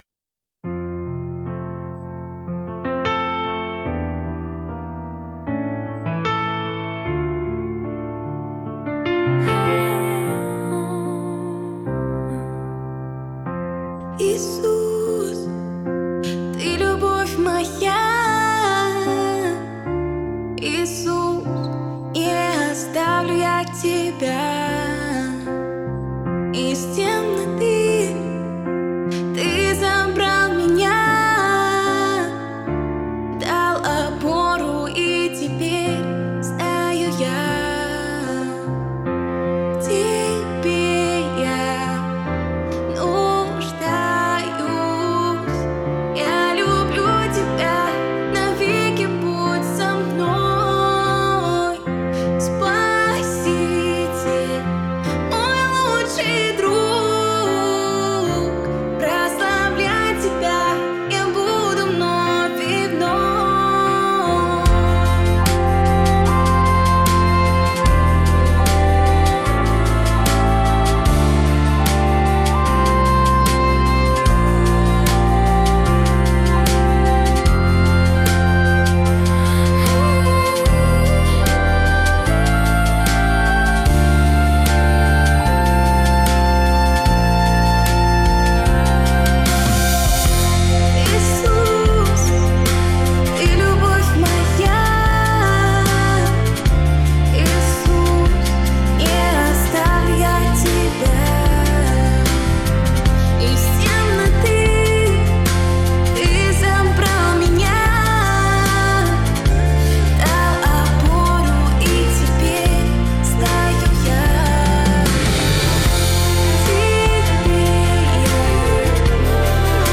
песня
296 просмотров 235 прослушиваний 9 скачиваний BPM: 75